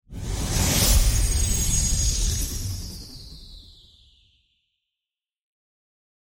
充電の瞬間を魔法のように強調し、オーディオエクスペリエンスに未曾有の次元を加える新しい効果音をお届けします。